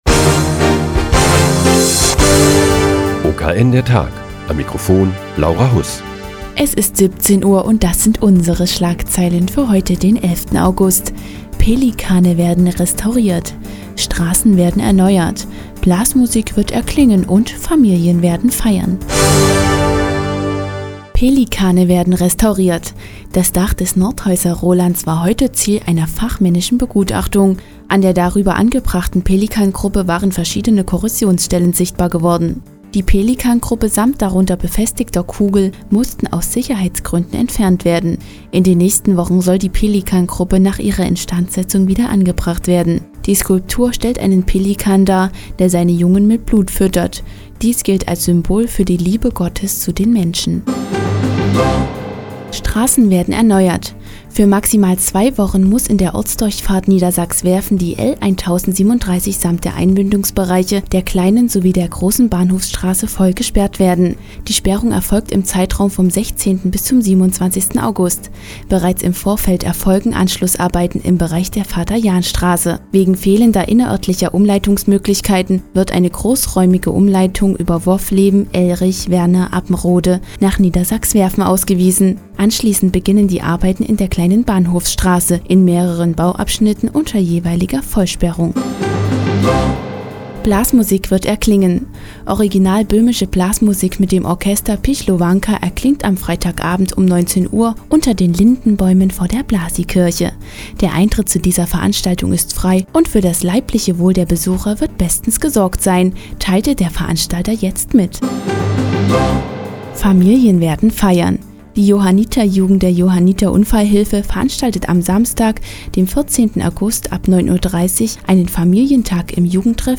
Die tägliche Nachrichtensendung des OKN ist nun auch in der nnz zu hören. Heute geht es um die Restaurierung der Pelikangruppe über dem Nordhäuser Roland und den Familientag der Johanniter- Jugend kommenden Samstag in Niedersalza.